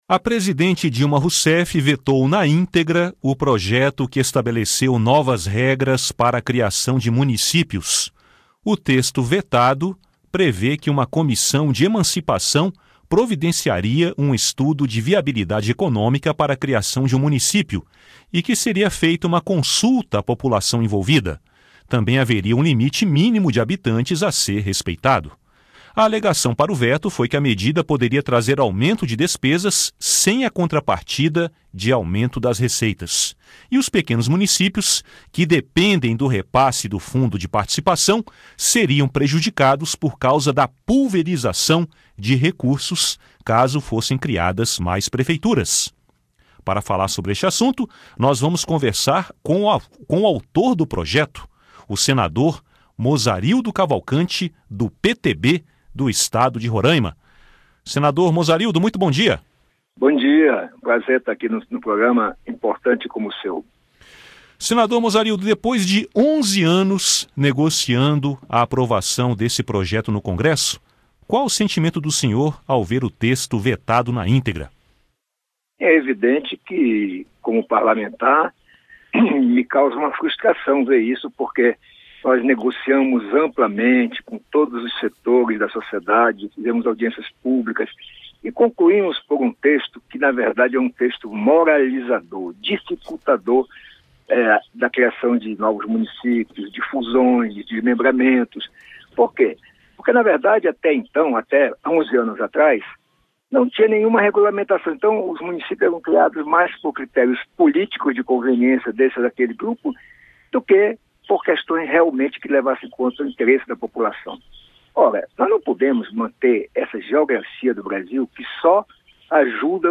Entrevista com o senador Mozarildo Cavalcanti (PTB-RR), autor da proposta